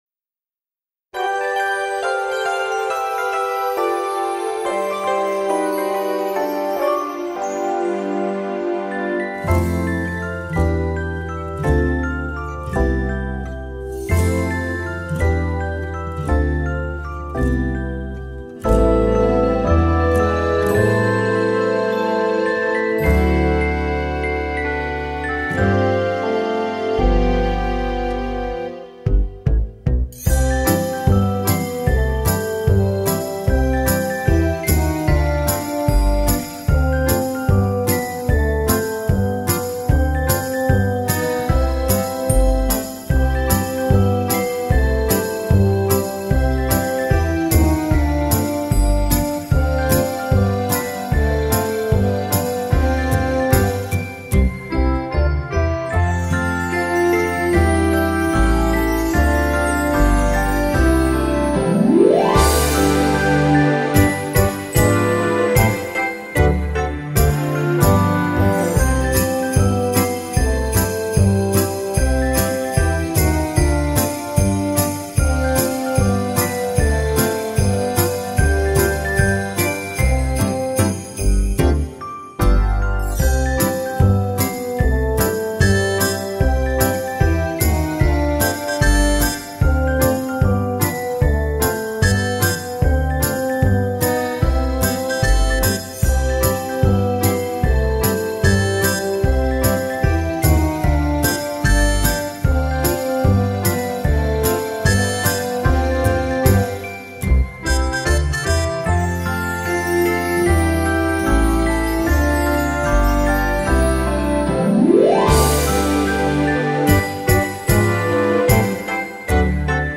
Base Instrumental: